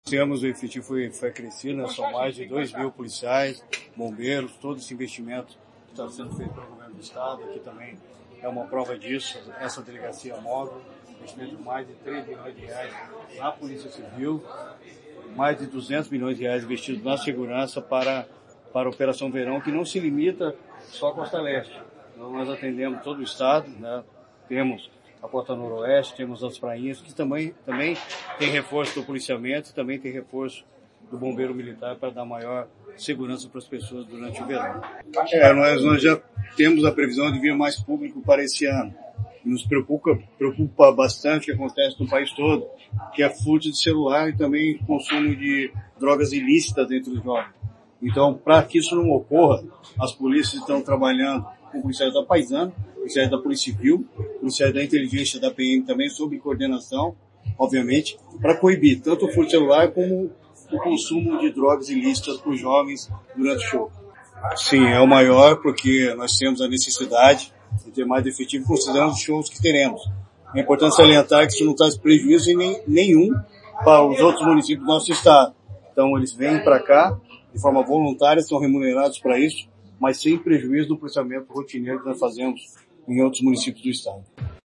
Sonora do secretário da Segurança Pública, Hudson Teixeira, sobre o lançamento do Verão Maior Paraná